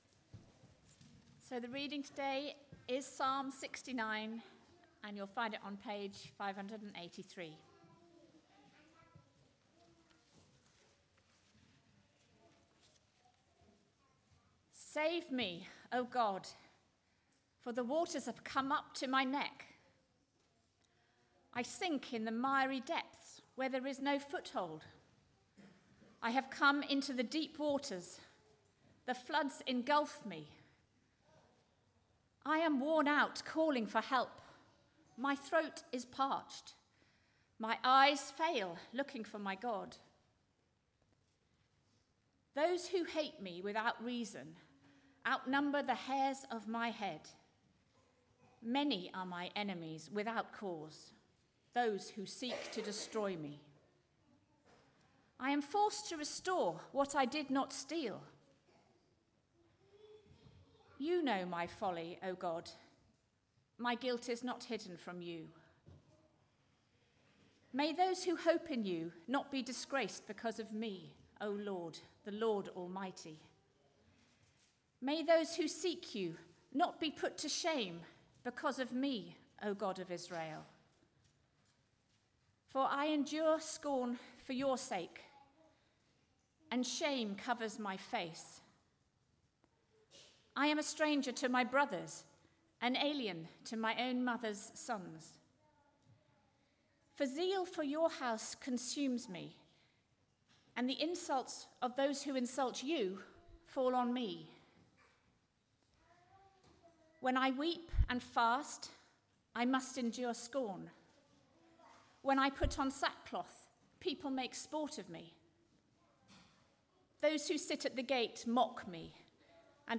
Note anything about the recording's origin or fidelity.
Media for Arborfield Morning Service on Sun 03rd Sep 2023 10:00